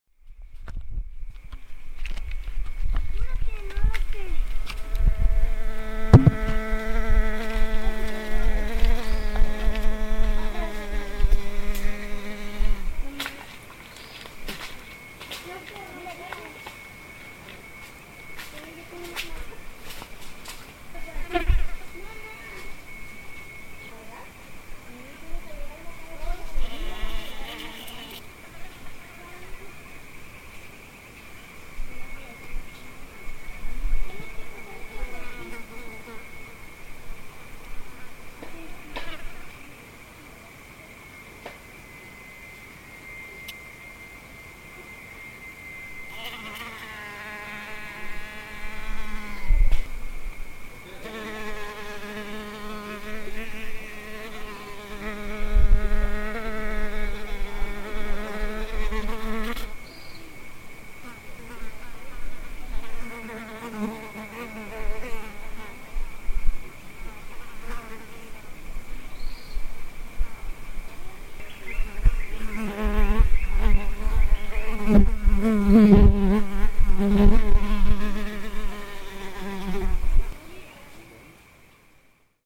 Insecto
Nos fuimos al rancho de Tencohuey, palabra náhuatl que significa "lugar en el margen o borde del cerro", localizado a 20 minutos de terracería de Azoyú, municipio del estado de Guerrero, Mexico para comer y bañarnos en el arroyo.
Mientras estábamos en esa tarea, deje el equipo de archivosonoro, al pie de un árbolito; los invito a escuchar el resultado de esa corta estancia, donde nuestro equipo de grabación recibió una visita inesperada.